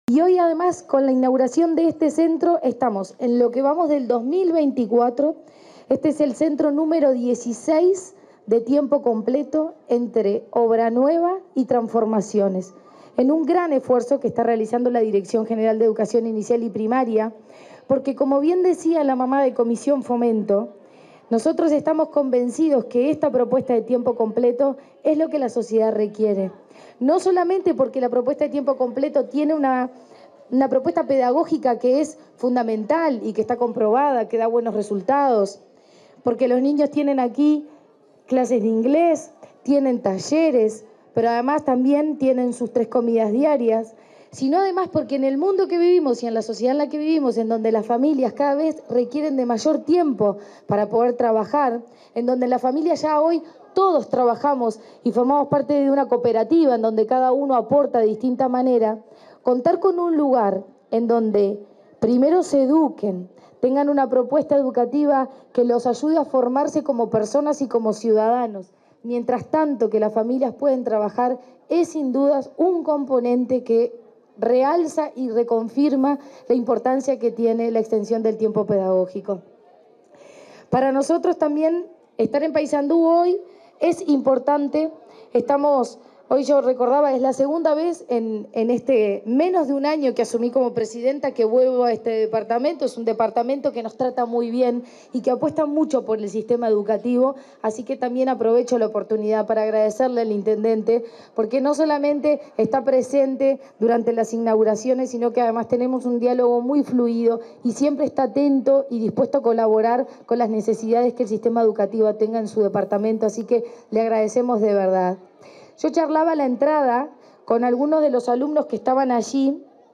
Palabras de la presidenta de ANEP, Virginia Cáceres
Palabras de la presidenta de ANEP, Virginia Cáceres 15/08/2024 Compartir Facebook X Copiar enlace WhatsApp LinkedIn En el marco de la ceremonia de inauguración de la escuela n° 117 de Tiempo Completo en Paysandú, este 15 de agosto, se expresó la presidenta de la Administración Nacional de Educación Pública, Virginia Cáceres.
caceres oratoria .mp3